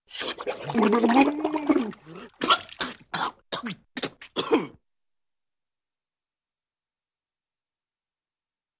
На этой странице собраны звуки тонущего человека в разных ситуациях: паника, борьба за жизнь, захлебывание водой.